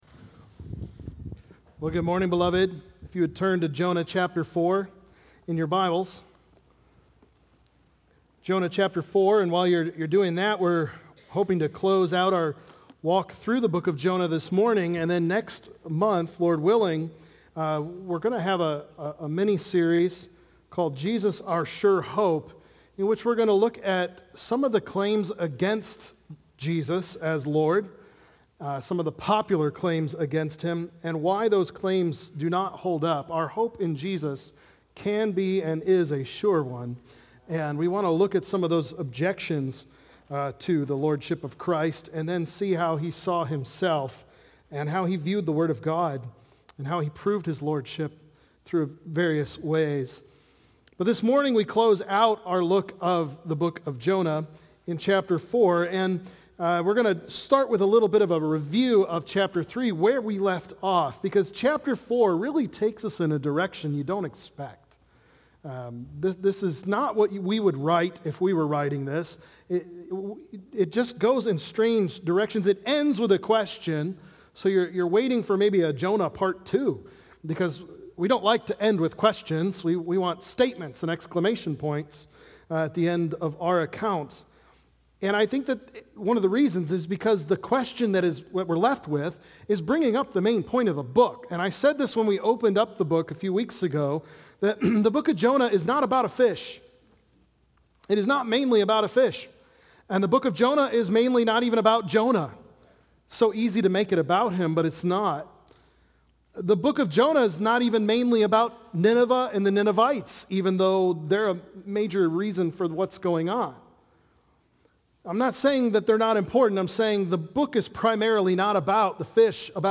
Man’s Anger vs. God’s Compassion | Baptist Church in Jamestown, Ohio, dedicated to a spirit of unity, prayer, and spiritual growth